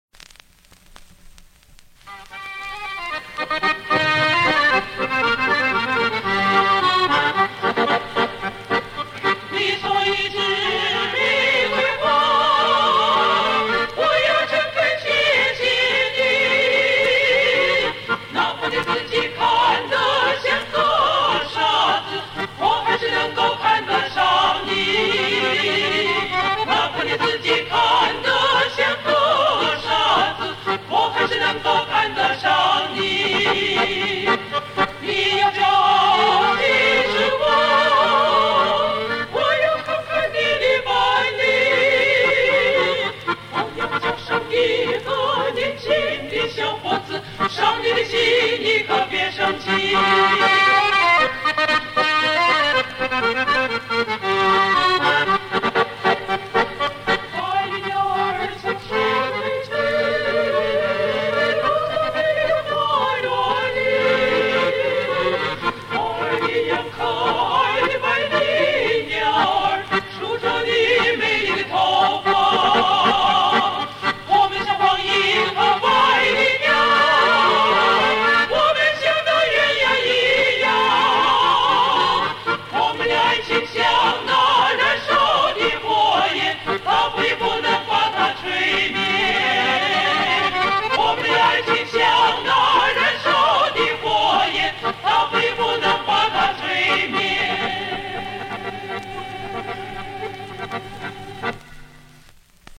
《送我一枝玫瑰花》 新疆民歌
女声小合唱 演唱： 中央音乐学院华东分院附中高中部女声合唱队
手风琴